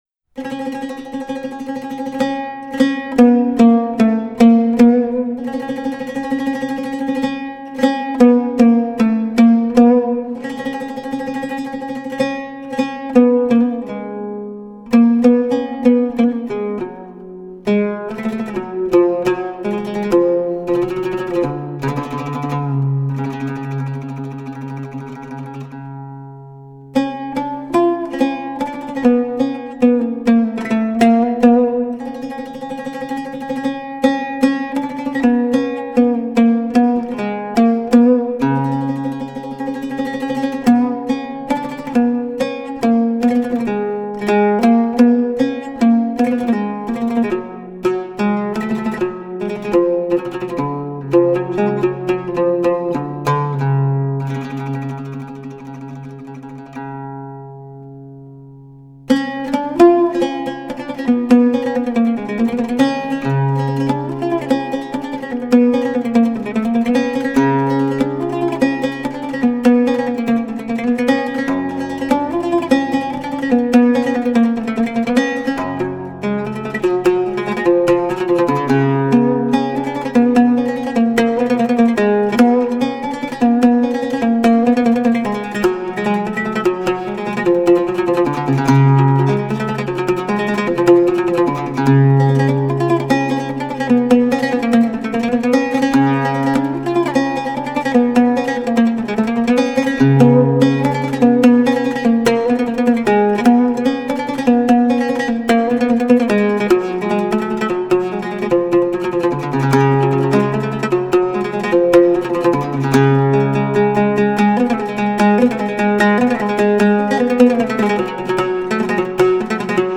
Transcript Music only